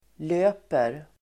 Uttal: [l'ö:per]